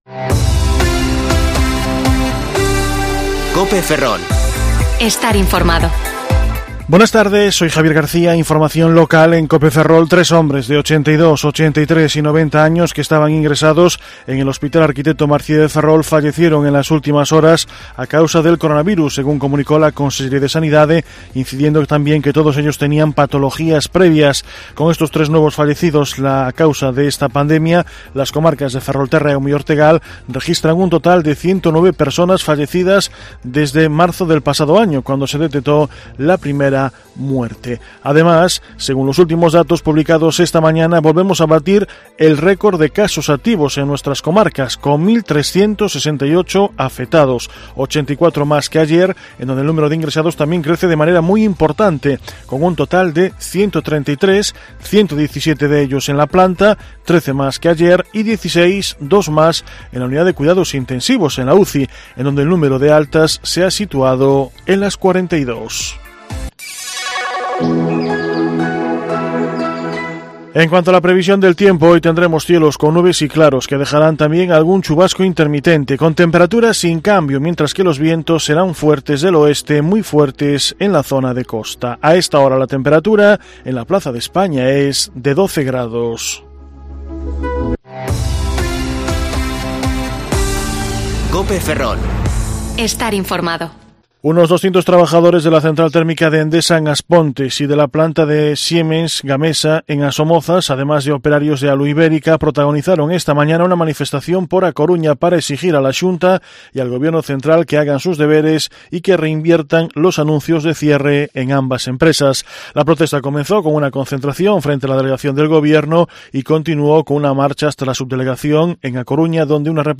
Informativo Mediodía COPE Ferrol 22/1/2021 (De 14,20 a 14,30 horas)